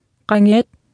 Below you can try out the text-to-speech system Martha.